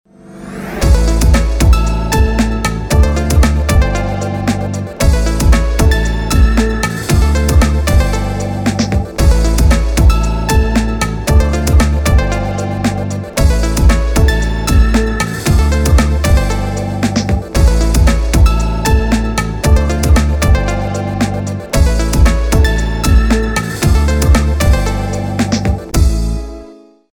-Tempo Cool